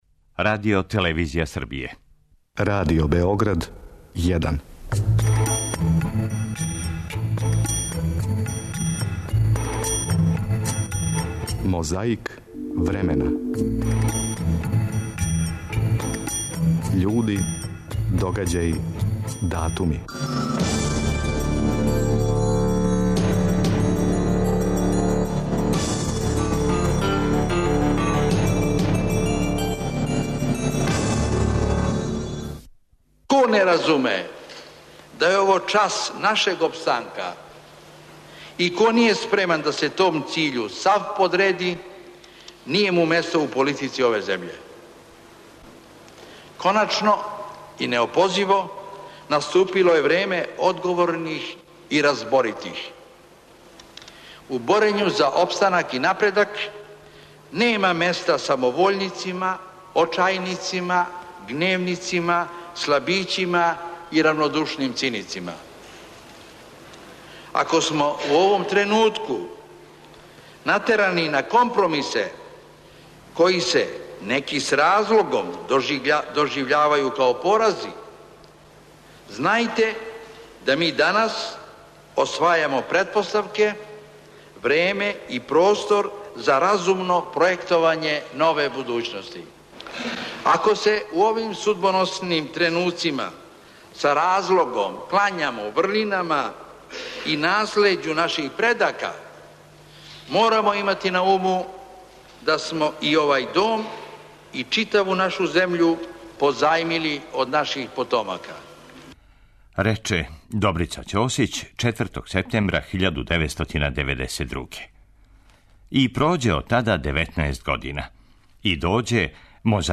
Да ли сте некада чули глас Краља Петра II?
Ту је белешка с једне од бројних мировних конференција током минулих ратних сукоба, конкретно поново Лондон, и то 1992.